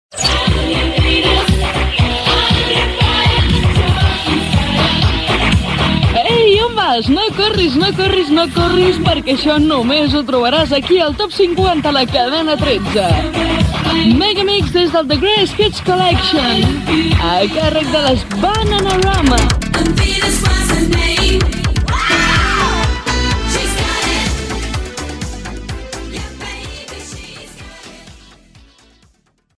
Identificació del programa i de la cadena i presentació d'un tema musical
Musical
FM